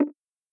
generic-hover-toolbar.wav